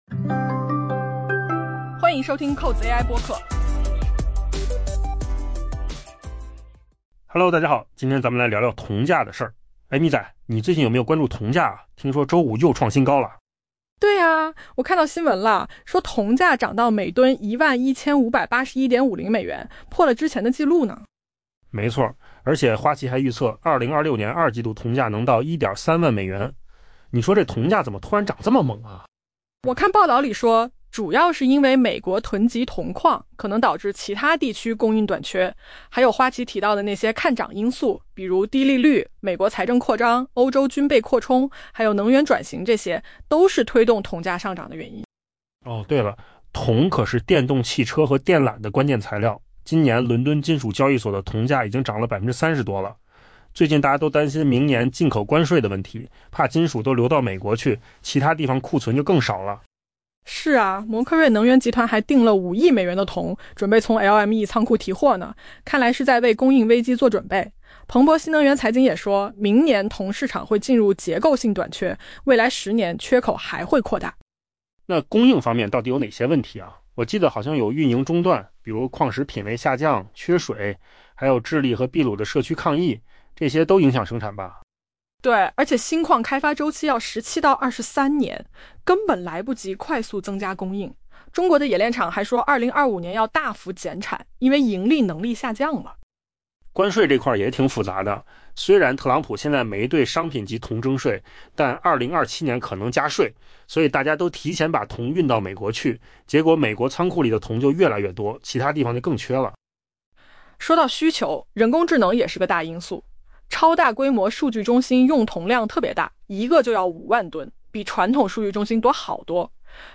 AI 播客：换个方式听新闻 下载 mp3 音频由扣子空间生成 受花旗发布的乐观价格展望推动，加之交易员预期美国囤货将导致铜短缺，铜价周五创下历史纪录。